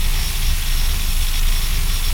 LASRBeam_Plasma Loop_01.wav